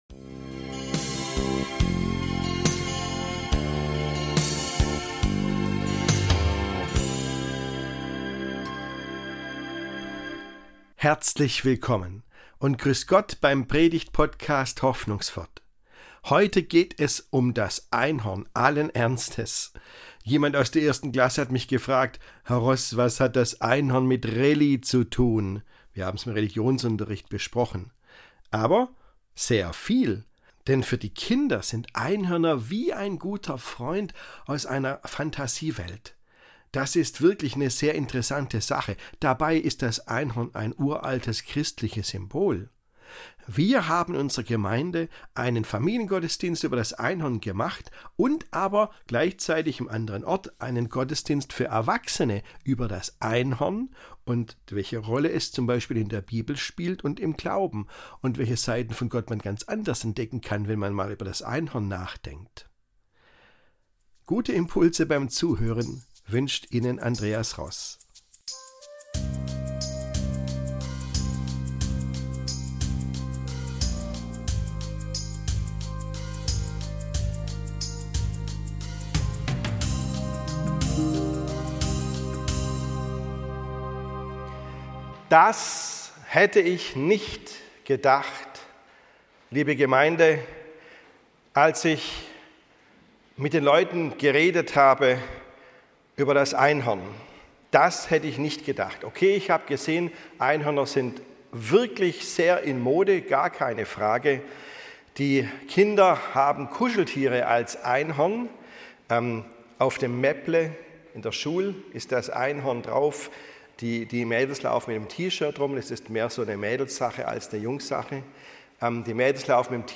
Jesus, das Einhorn ~ Hoffnungswort - Predigten
Wussten Sie, dass das Einhorn ein altes christliches Symbol ist? Was das Einhorn mit der Bibel und mit dem Glauben zu tun hat und wie es uns helfen kann, mal ganz andere Seiten von Gott wahrzunehmen, darum geht es in dieser Predigt (1.6.2025).